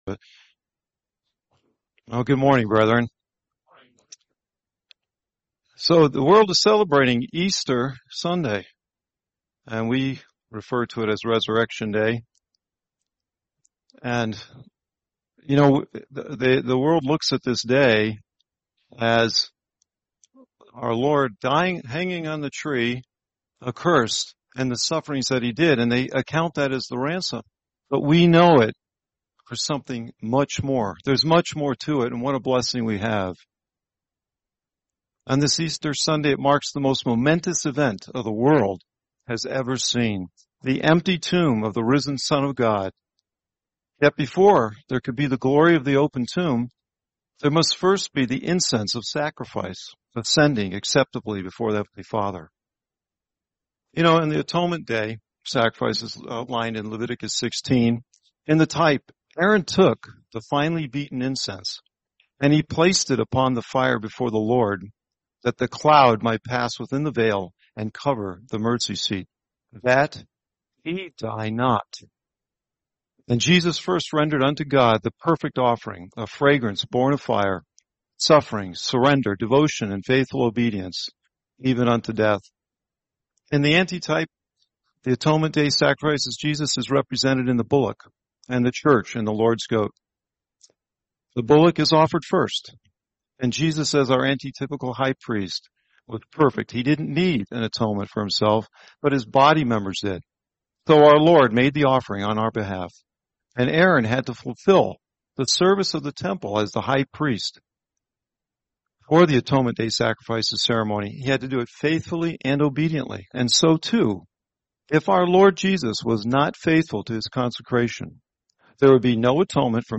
Series: 2026 Albuquerque Convention